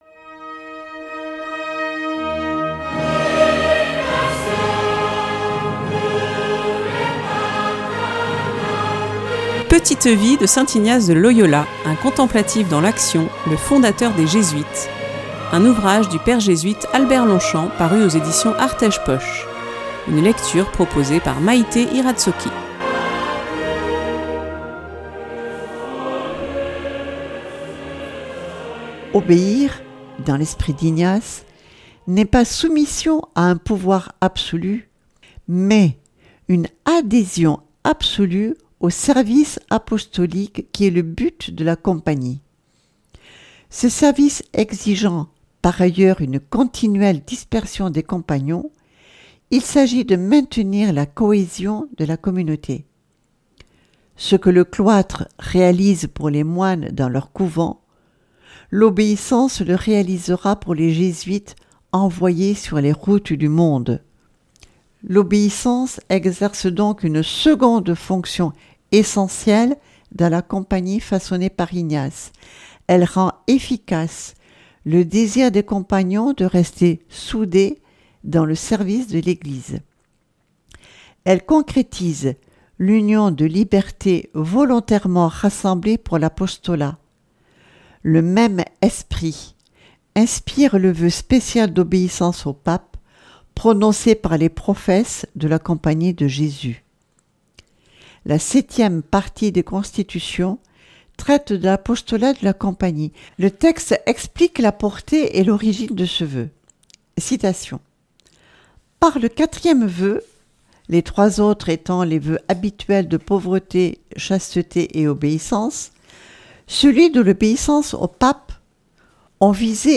Une lecture